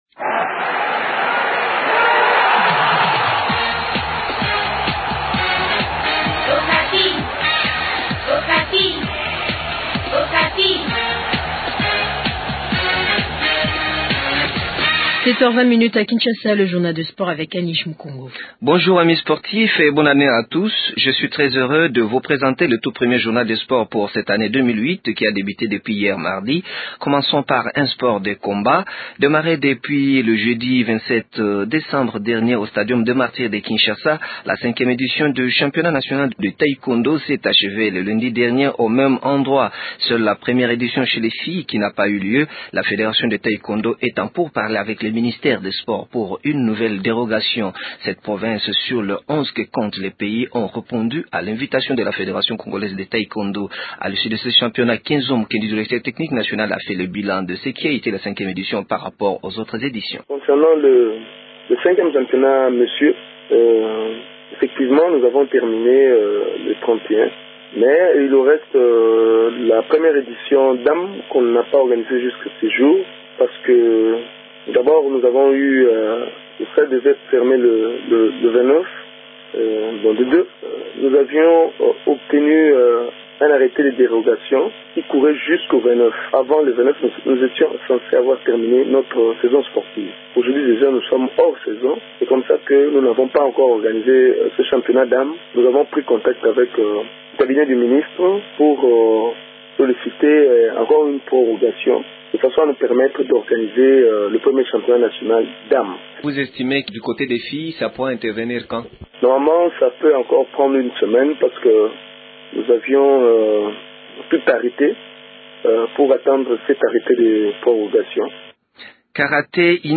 Il est joint au téléphone ce matin par Radio Okapi.